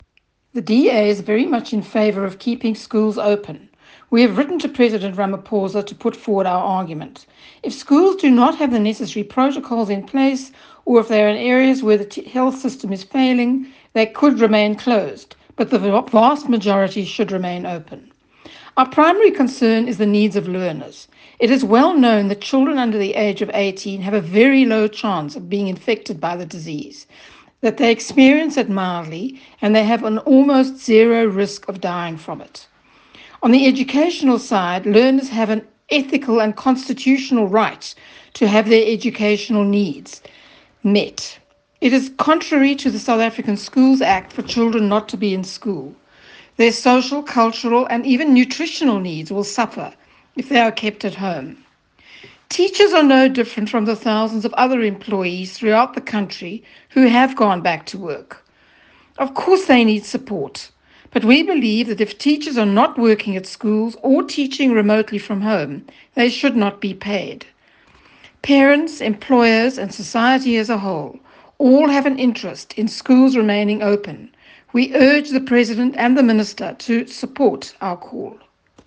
English soundbite by Prof Belinda Bozzoli MP.
The following comments were delivered during a virtual press conference on the opening of schools.